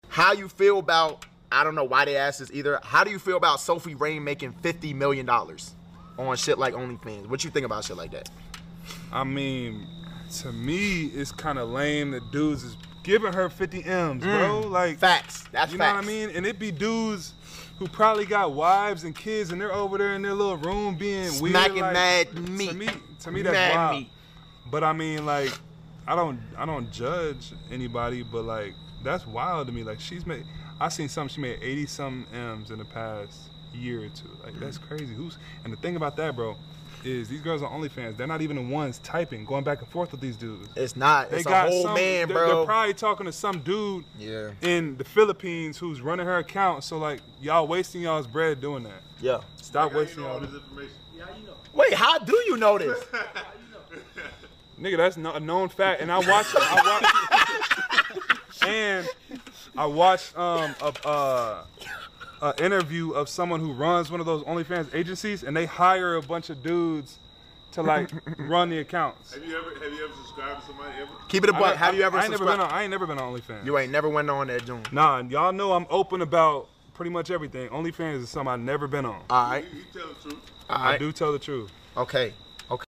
NBA star Michael Porter Jr. speaks on Sophie Rain making $82 million last year on PlaqueBoyMax’s stream